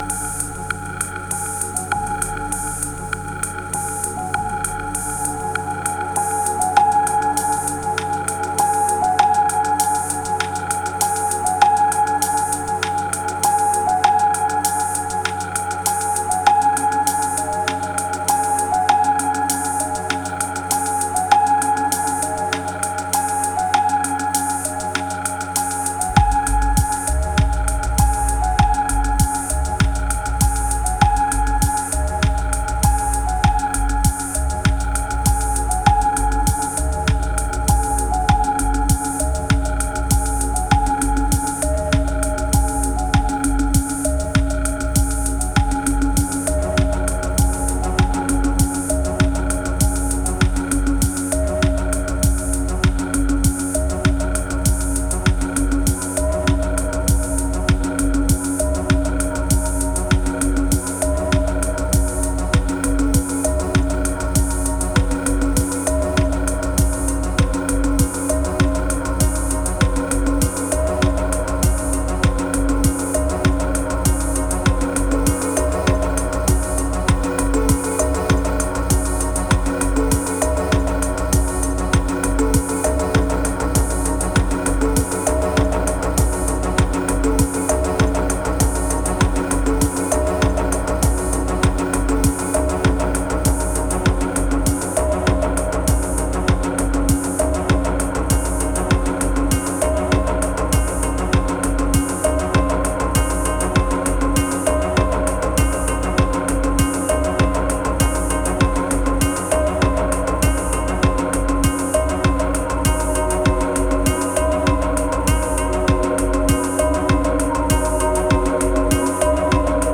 1053📈 - 76%🤔 - 99BPM🔊 - 2024-01-28📅 - 590🌟